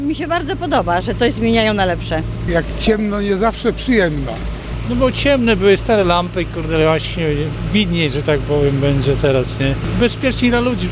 Zapytaliśmy mieszkańców Stargardu co myślą na temat tego typu inwestycji w naszym mieście.